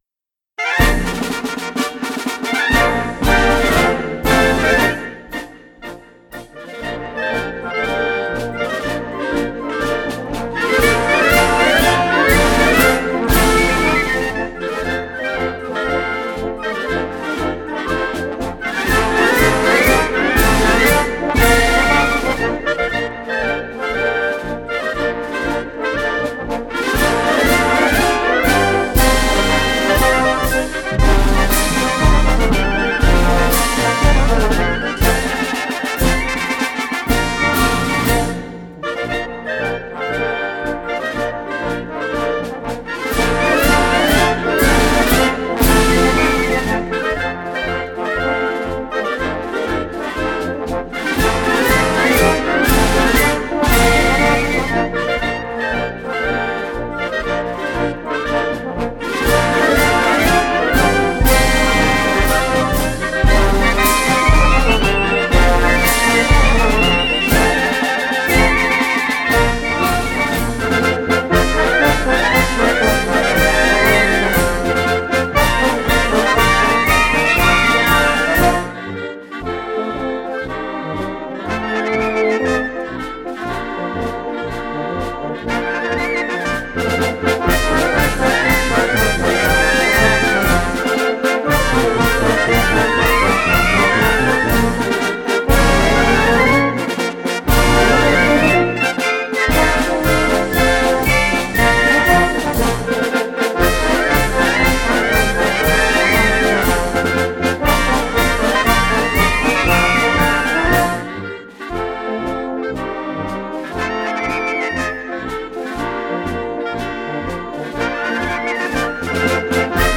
Una adaptación del toque de guerra, a manera de fanfarria, abre la marcha con un tono alegre y festivo para dar paso al tema principal, que evoca el tono en que decimos las primeras líneas del papel del Rey Cristiano en nuestra Relación: “En el nombre sea de Dios y de la Purísima Concepción de María”. En momentos escucharemos el Canto del Sabario, con trompetas haciendo contrapunto con los trombones barítono y la tuba, adornando los clarinetes sin perder el ritmo de la sección de percusiones.
El toque militar, con su remate, da entrada al trío donde el flautín y el clarinete adornan la melodía con notas alegres. La intensidad sube para dar paso al puente, toque marcial, y entrar a la relación para dar a una marcha alegre que representa el triunfo de los Cristianos y el fin de nuestra representación.
Flautín
Clarinetes
Saxofón alto
Trompetas
Trombones
Barítono
Corno
Tuba
Percusiones